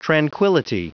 Prononciation du mot tranquility en anglais (fichier audio)
Prononciation du mot : tranquility